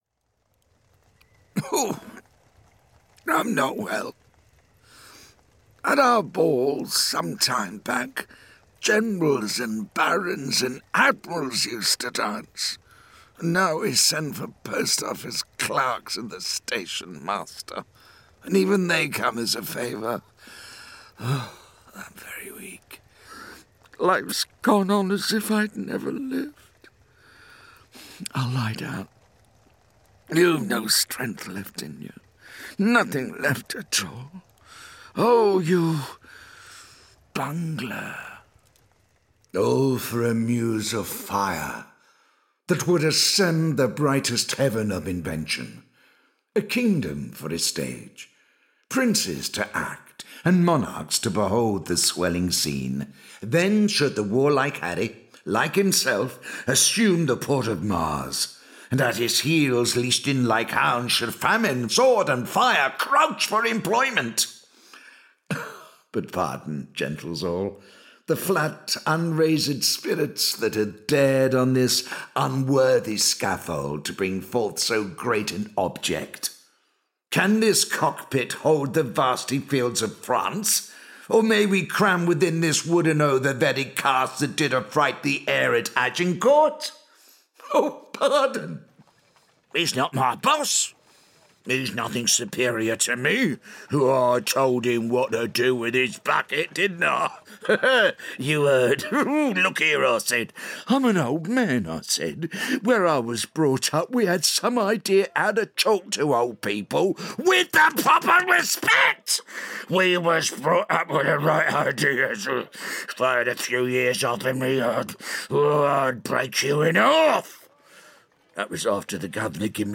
Audio Drama Showreel
The ultimate deep, dark, gravelly British voice, perfect for anything from dramatic and sincere to menacing and sinister. Mature, paternal, and reassuring, with a hint of thespian flair.
Male
Neutral British
Gravelly
Smooth